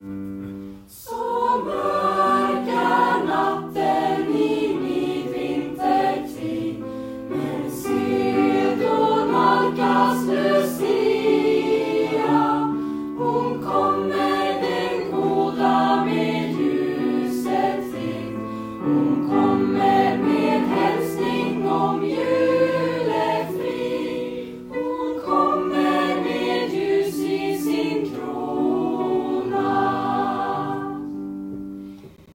I Nobelfestens skugga avslutades föreningsåret med en liten lucia-fest.
Elever från Djurgårdsskolan framförde ett stillsamt men väldigt vackert framträdande vid sammankomsten i ReTuna.